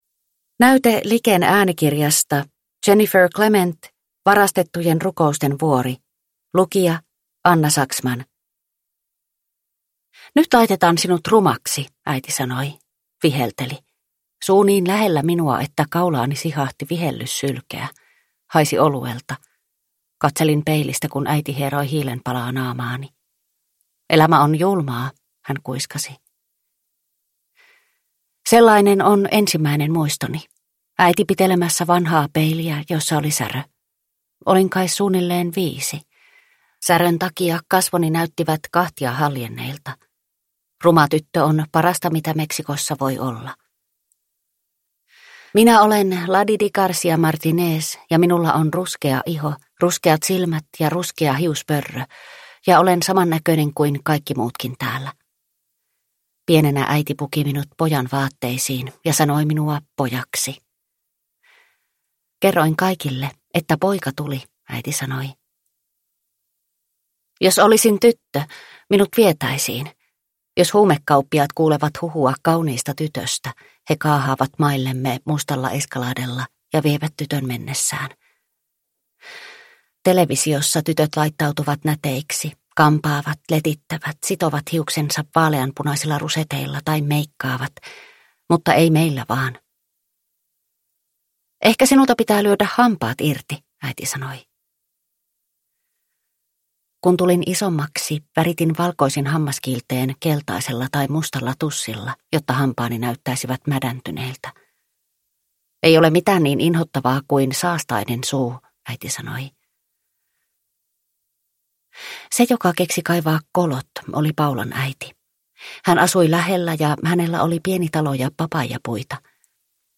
Varastettujen rukousten vuori – Ljudbok – Laddas ner